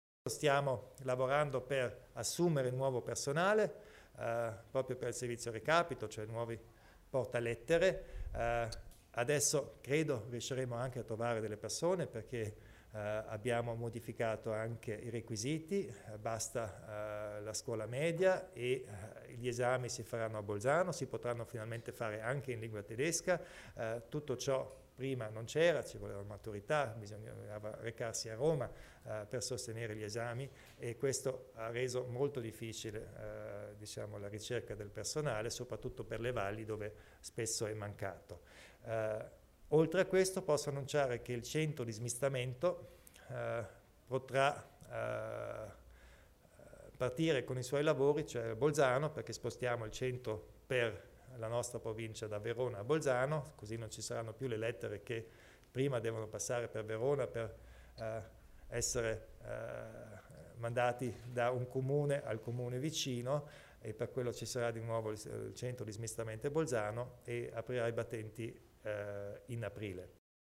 Il Presidente Kompatscher elenca le importanti novità per la distribuzione della posta in Alto Adige